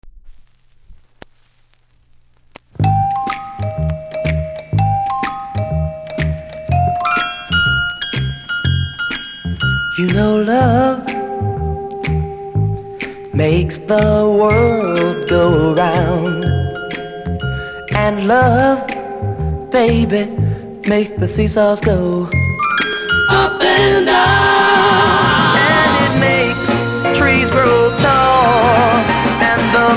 出だしに数発ノイズありますので試聴で確認下さい。